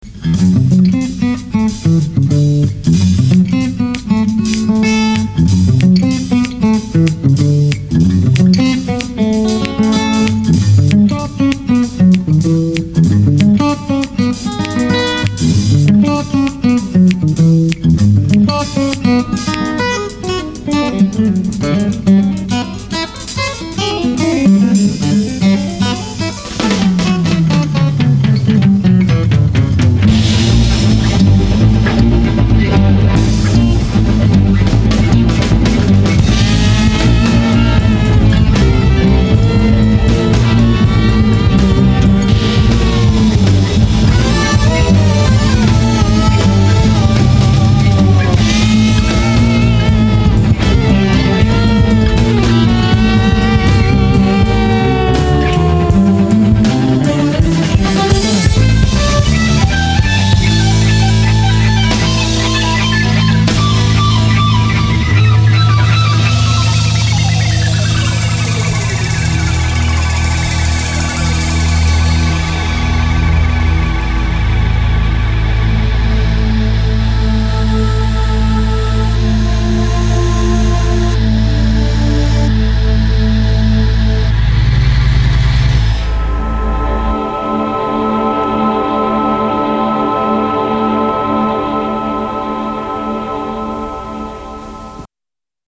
Carvin and 67 Telecaster guitars, bass
Drums